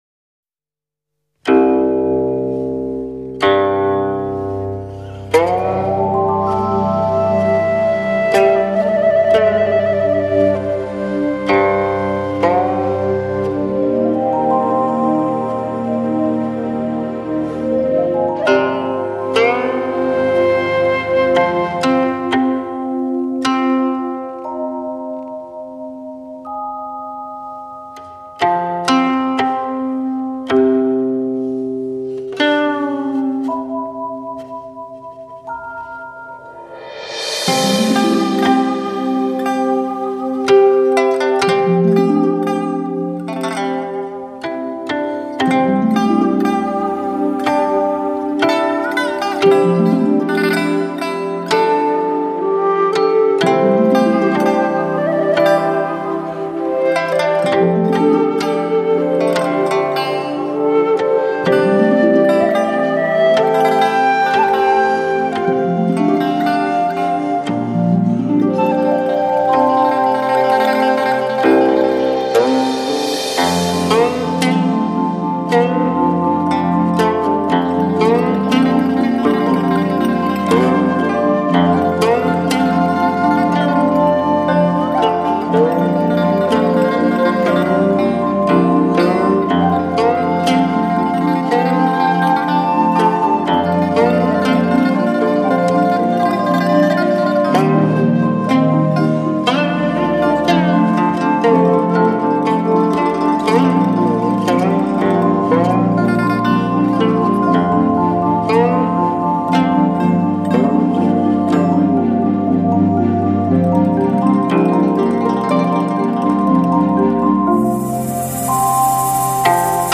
古琴幽幽，清音袅袅，轻轻吟唱令人心醉！
古琴轻拨细弹，琴声铮铮有声，如行云流水，轻声吟唱配合，如诗如幻，营造了一个好美的意境
古琴幽幽、轻声吟唱